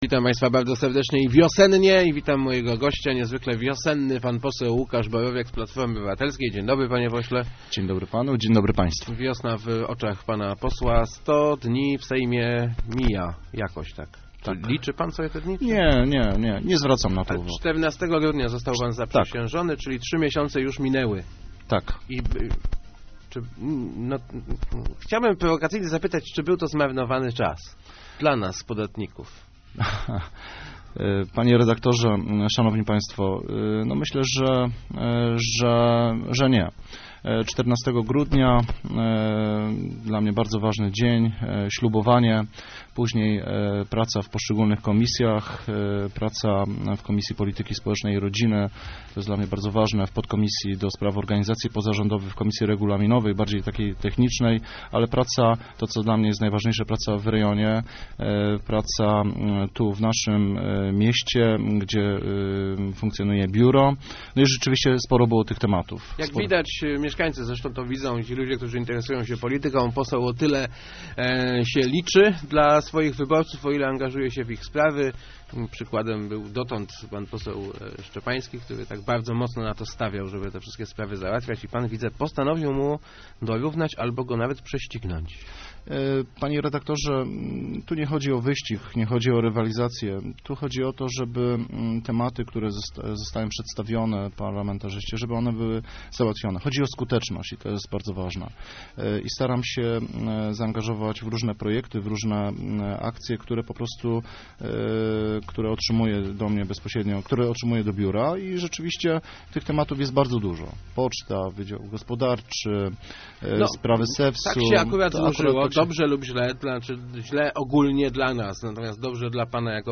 Pozostawienie wydzia�u gospodarczego s�du w Lesznie nie by�o �adnym politycznym prezentem - mówi� w Rozmowach Elki pose� PO �ukasz Borowiak. Jak przyzna� nie wie, co sprawi�o, �e wydzia� w ogóle trafi� na list� do likwidacji, jednak podkre�la, �e rozmowy parlamentarzystów i samorz�dowców z ministrem przynios�y skutek.